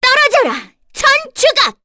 Cecilia-Vox_Skill4_kr_b.wav